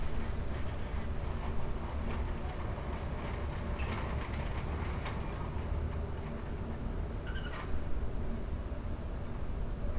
urban